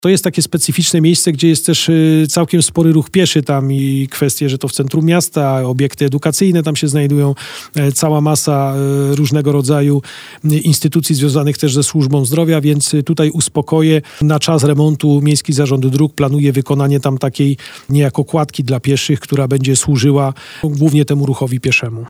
Temat powraca co jakiś czas, my poruszyliśmy go w rozmowie z wiceprezydentem miasta.